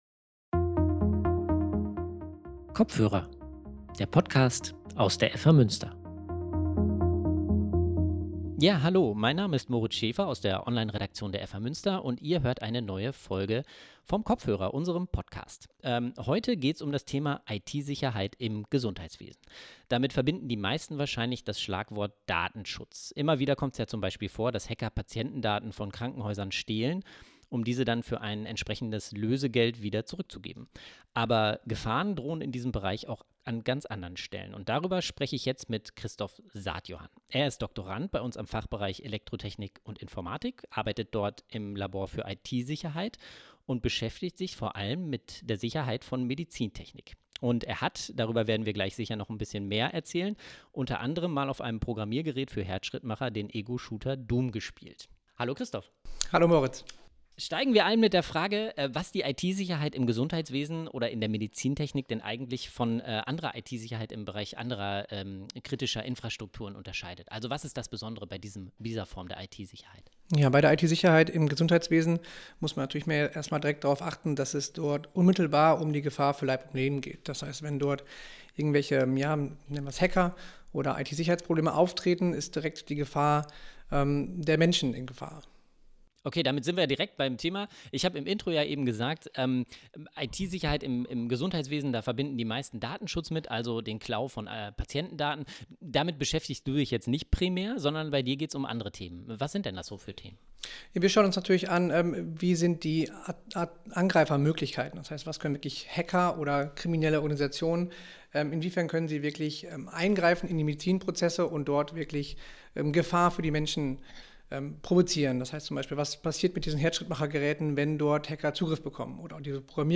Darum geht's im Gespräch